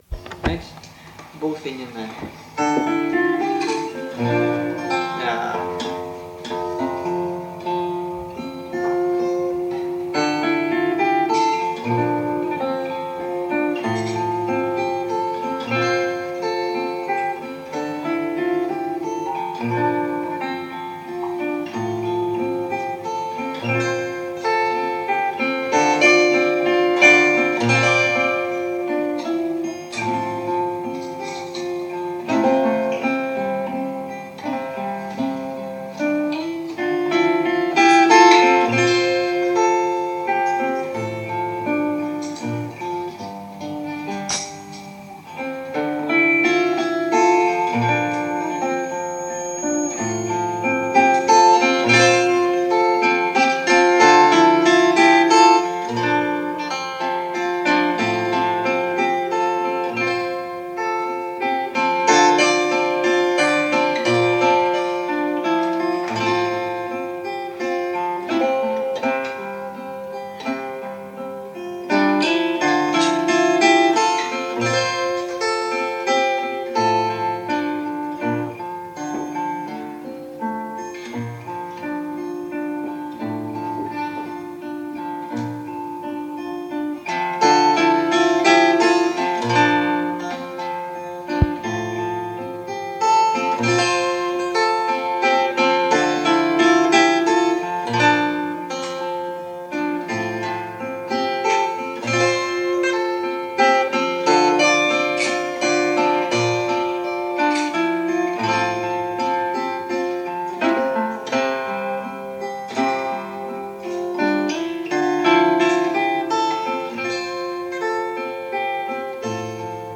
playing fiddle